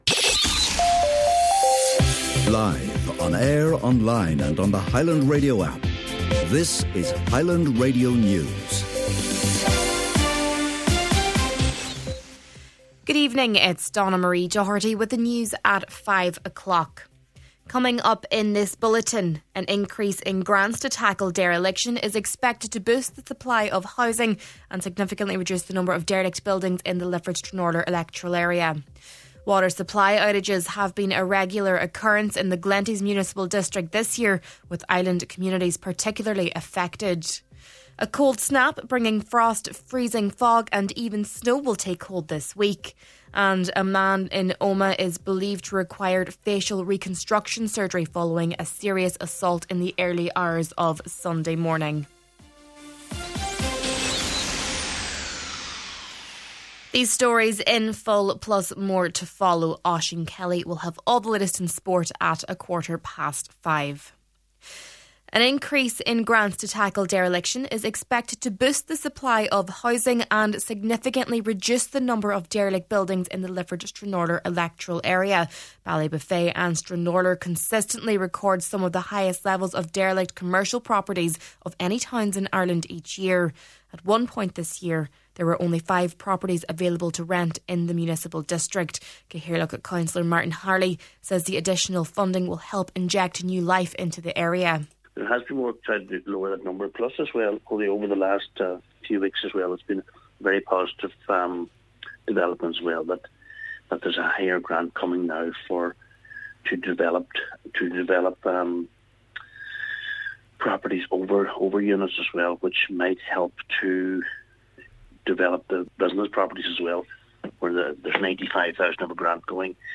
Main Evening News, Sport and Obituary Notices – Tuesday, December 30th